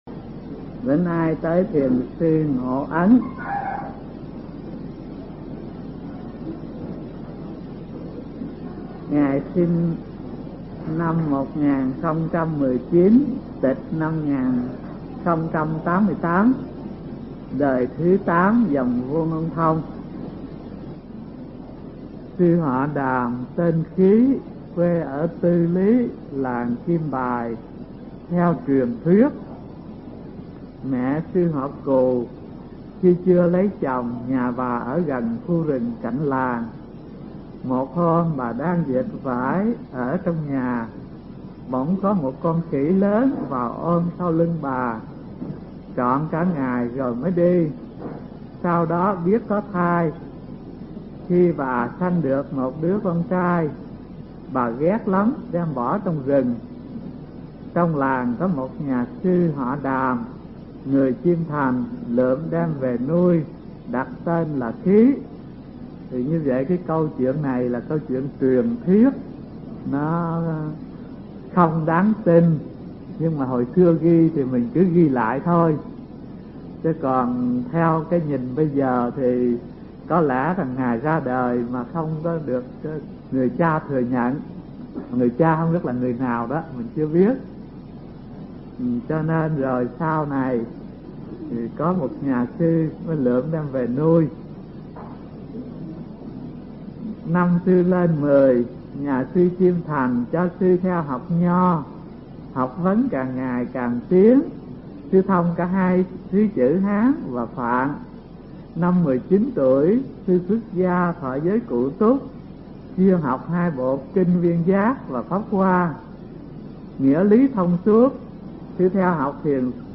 Mp3 Pháp Âm Thiền Học Phật Giáo Việt Nam 77 – Ngộ Ấn (Đời 8 Dòng Vô Ngôn Thông) – Hòa Thượng Thích Thanh Từ giảng tại trường Cao Cấp Phật Học Vạn Hạnh, từ năm 1989 đến năm 1991